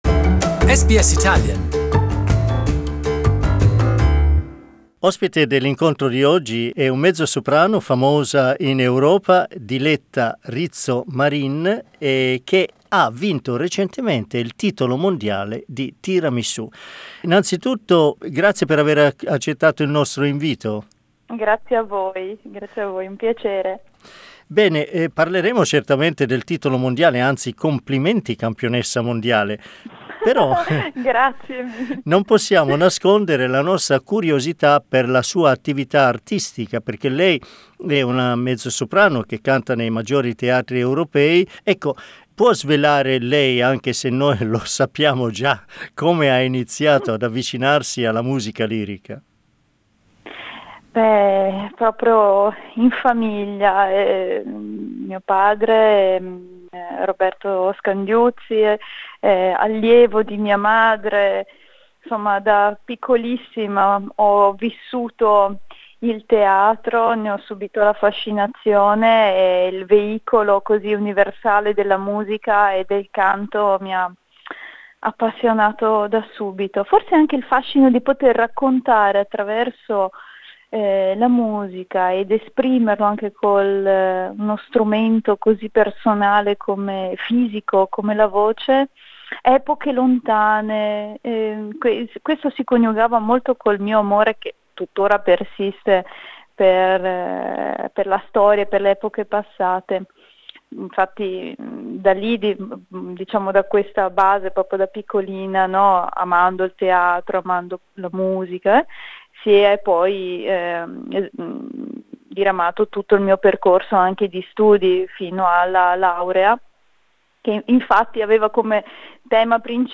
In this interview she talks about her brilliant singing career and how she became a dessert world champion.